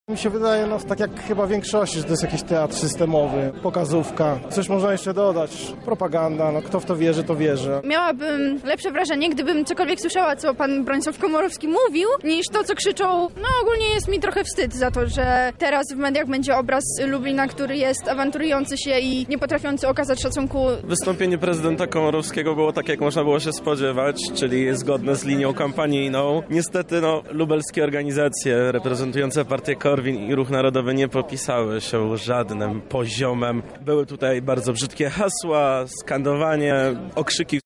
Sprawdźmy jak przemówienie odebrali mieszkańcy Lublina?